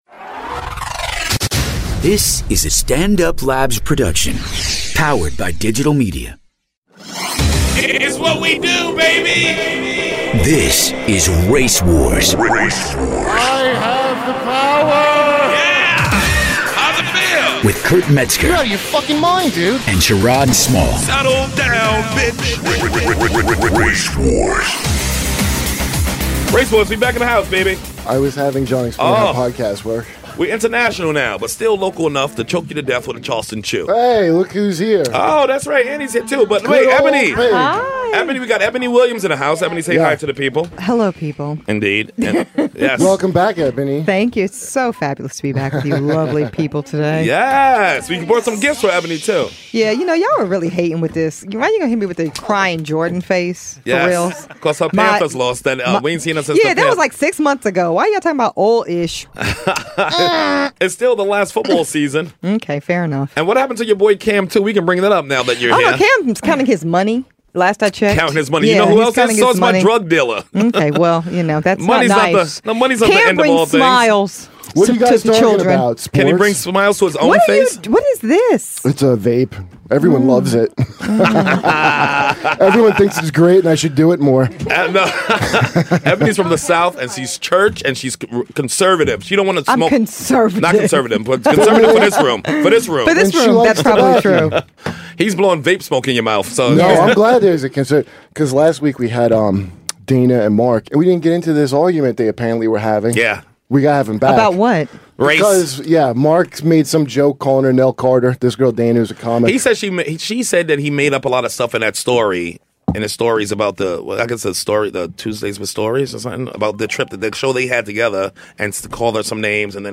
in studio this week